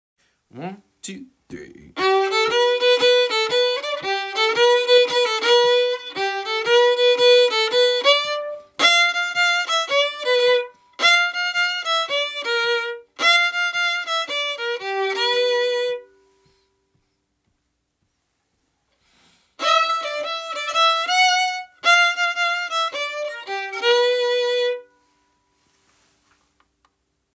Milk Cow Blues harmony (MP3)Download